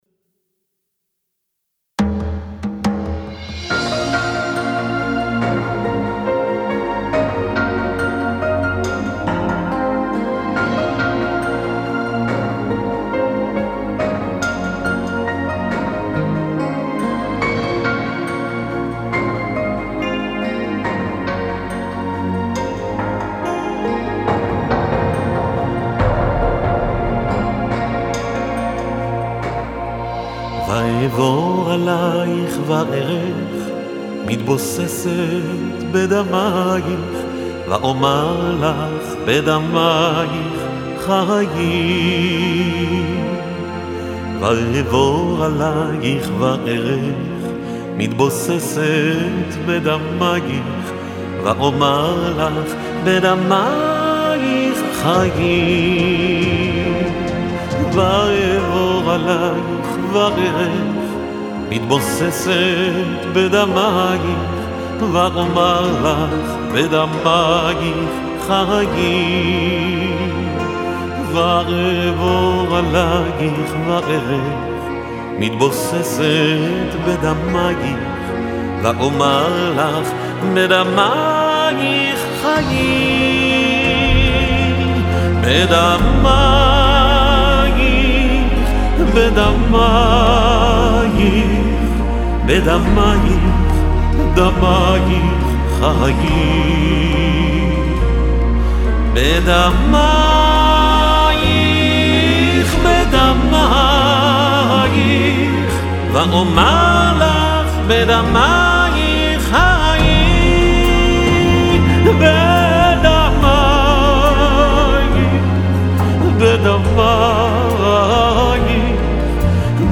ומוסיף לו נימה אישית ומרגשת